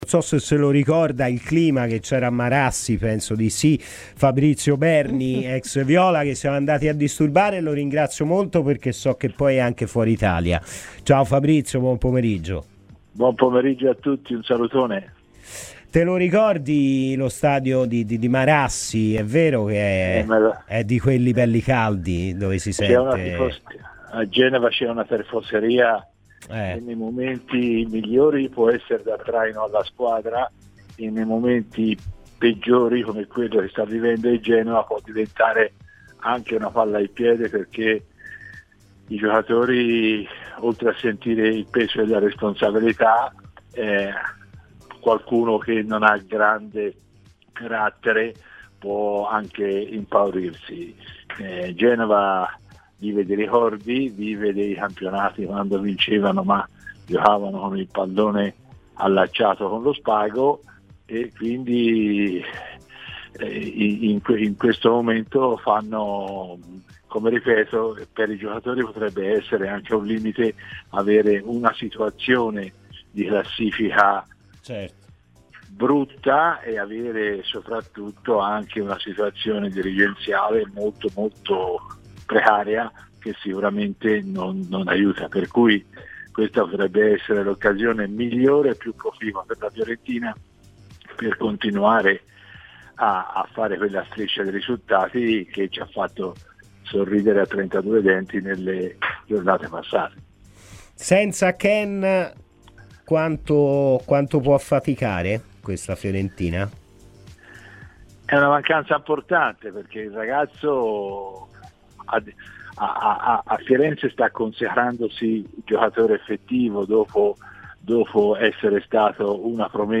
è intervenuto ai microfoni di Radio FirenzeViola durante la trasmissione "Palla al Centro" poche ore prima del match tra Genoa e Fiorentina.